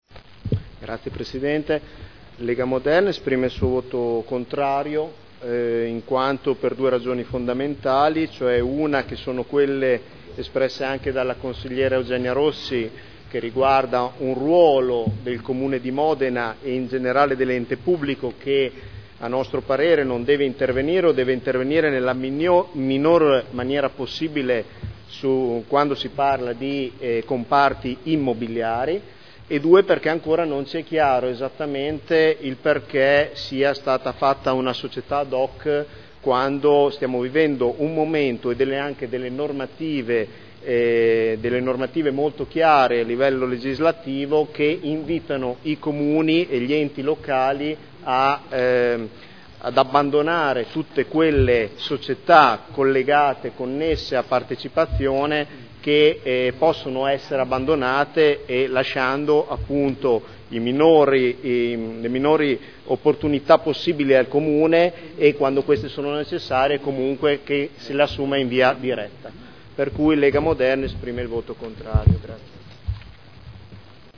Seduta del 22/12/2011. Dichiarazione di voto su proposta di deliberazione.